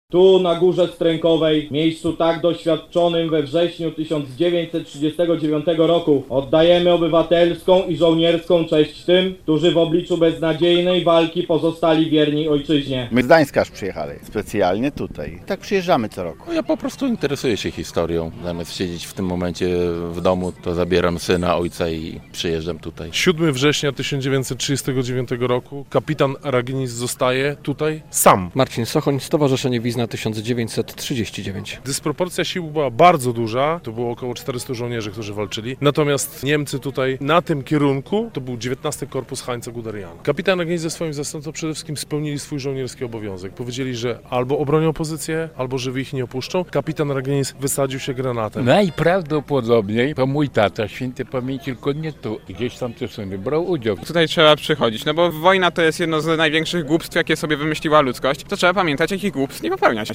86. rocznica bitwy pod Wizną - relacja